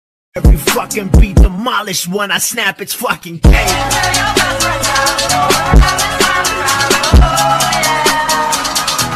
sang happy birthday in cursive